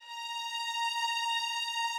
Added more instrument wavs
strings_070.wav